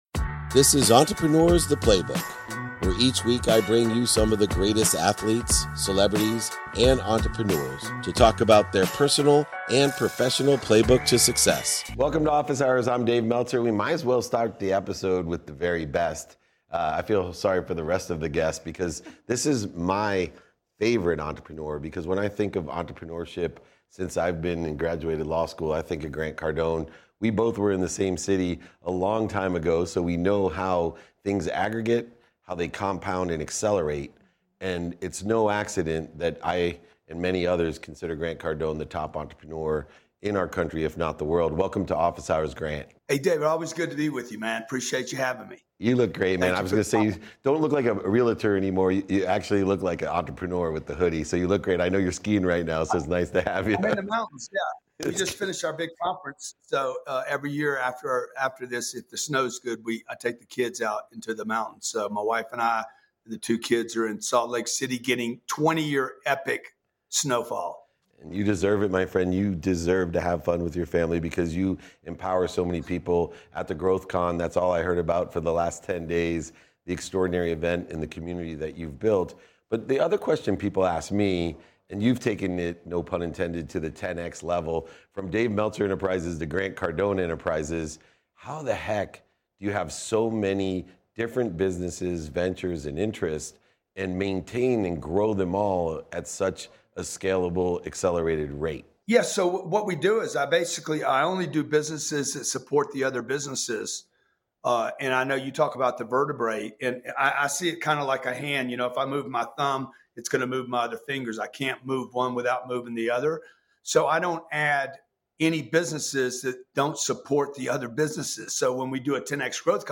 Today's episode is from a conversation with Grant Cardone from a recent episode of my show Office Hours.